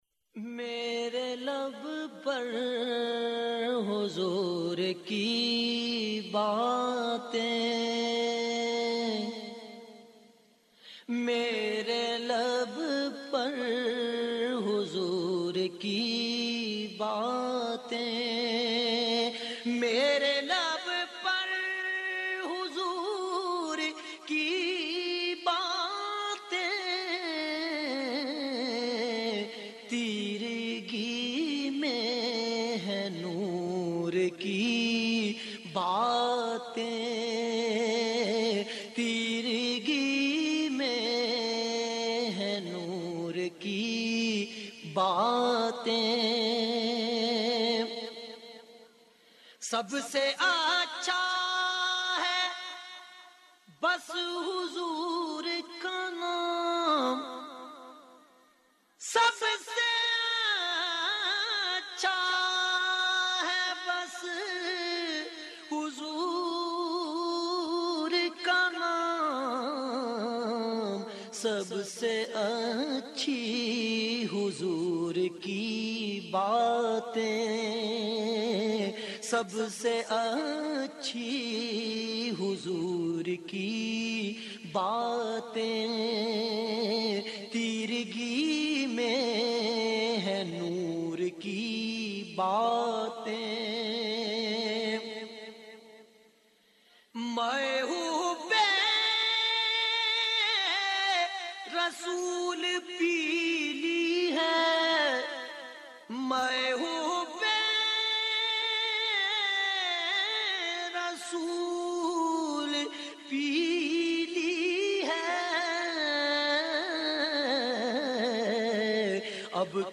نعت رسول مقبول صلٰی اللہ علیہ وآلہ وسلم